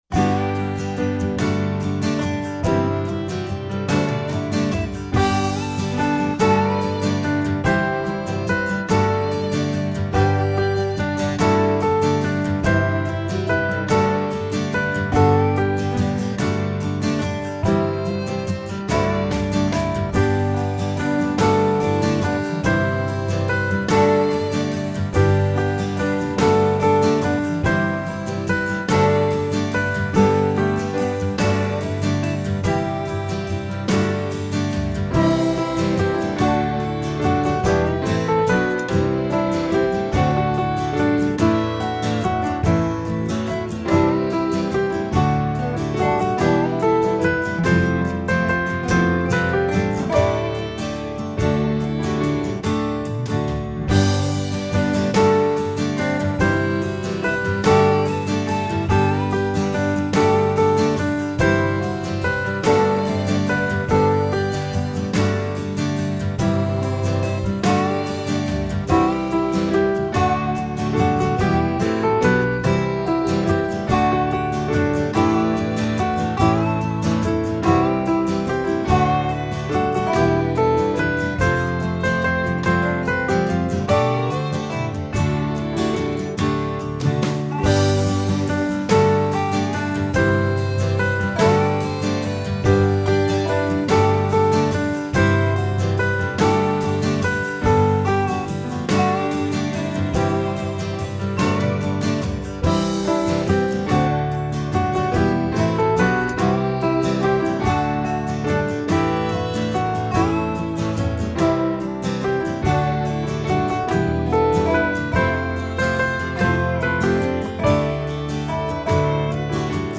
joyful liturgically useful song